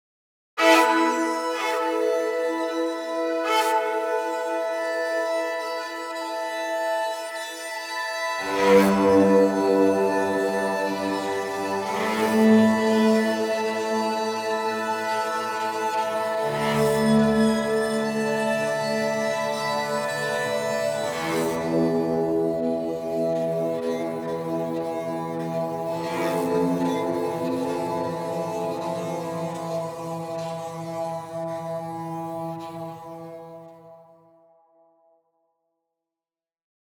There are lovely scratchy bowed sounds that percolate with sizzling harmonics.
Below are a few sound examples recorded without any post-processing effects.
Using Rip and Sul Pont sources